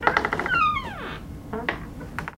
Doors-Wood
Apartment or Office Wooden Door Squeak, Varied